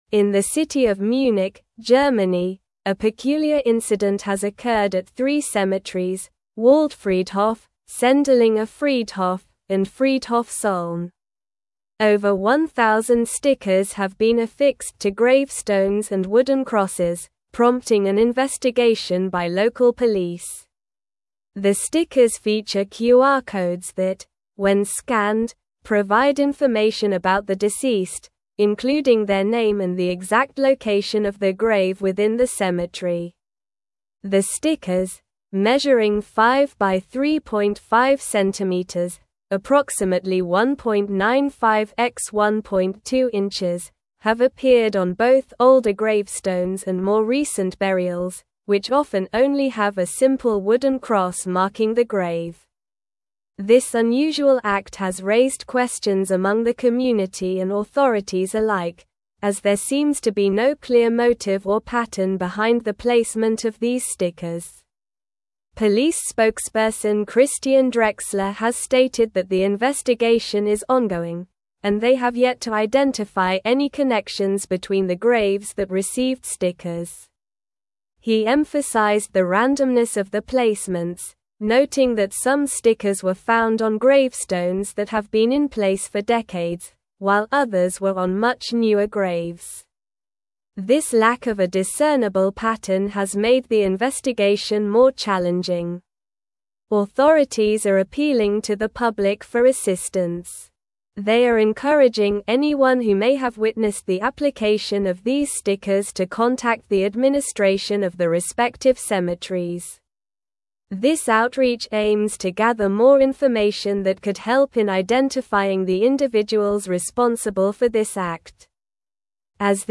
Slow
English-Newsroom-Advanced-SLOW-Reading-QR-Code-Stickers-Appear-on-Gravestones-in-Munich.mp3